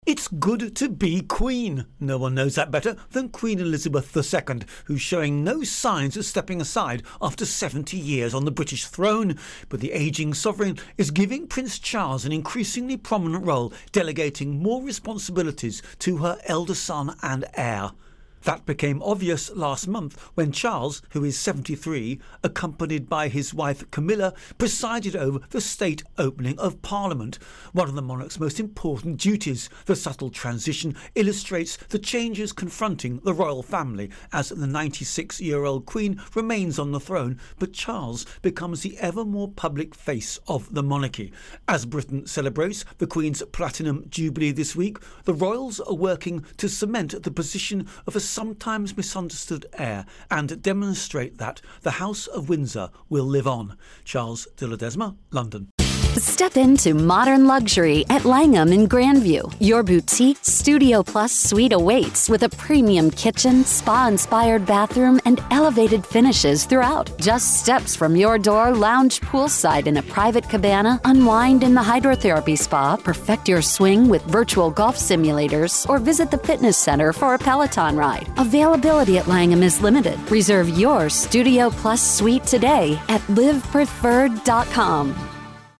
Britain Platinum Jubilee Prince Charles Intro and Voicer